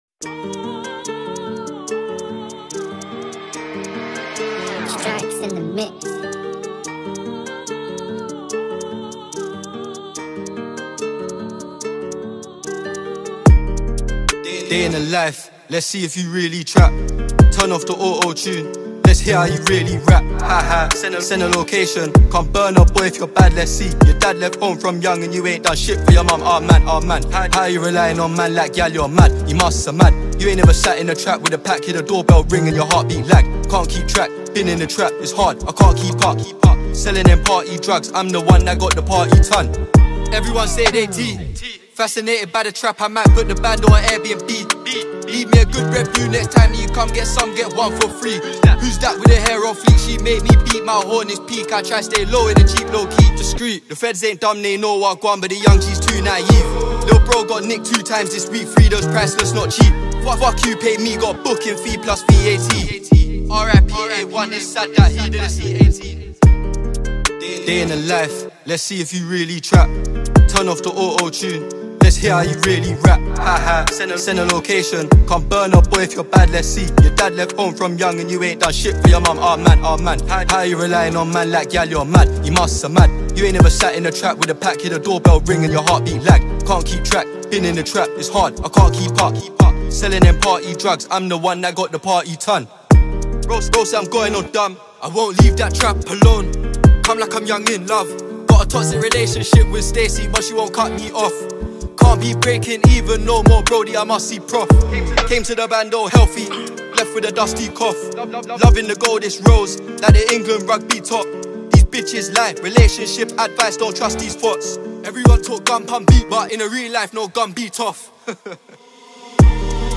Tags: Music